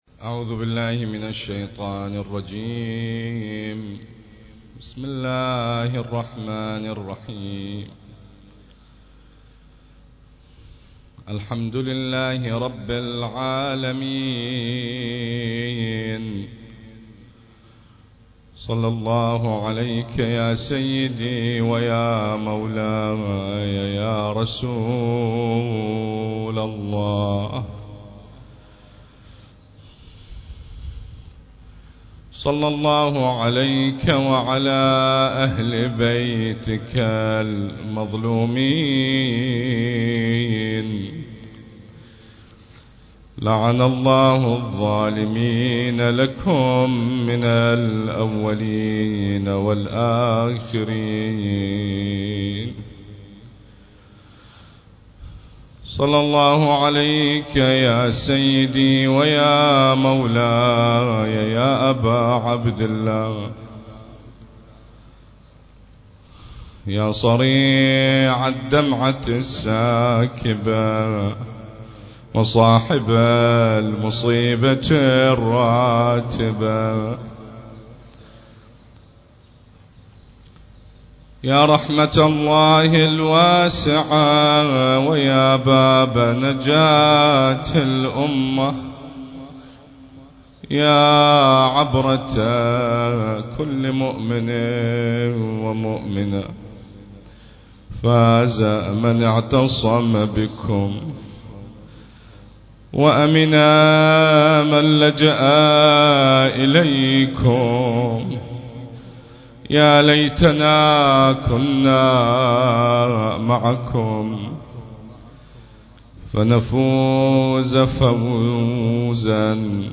المكان: حسينية المرحوم الحاج داود العاشور - البصرة